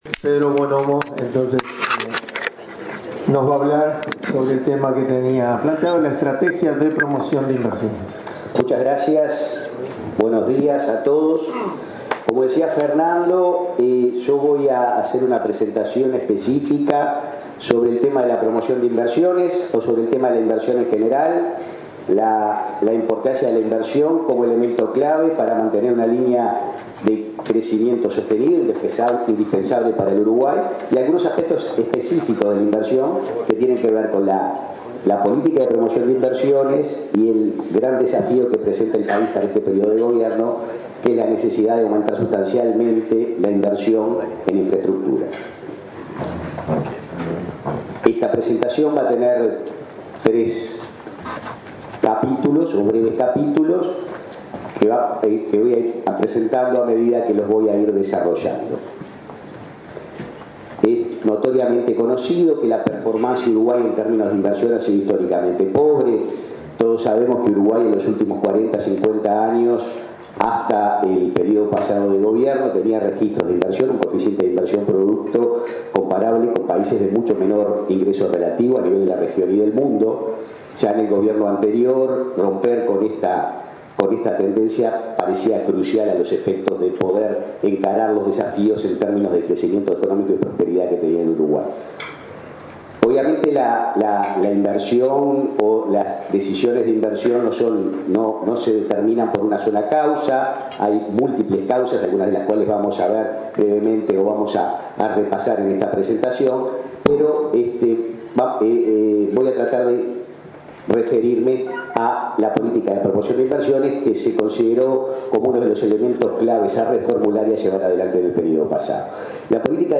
Durante el Foro Econ�mico de ACDE (Asociaci�n Cristiana de Dirigentes de Empresa), el Subsecretario Pedro Buonomo hizo referencia a la estrategia del Gobierno en torno a la promoci�n de inversiones. Afirm� que Uruguay viene de 40 a�os con niveles muy bajos de inversi�n, en comparaci�n con pa�ses m�s pobres, por lo que este tema se configur� como uno de los principales aspectos que trat� el Gobierno de Tabar� V�zquez.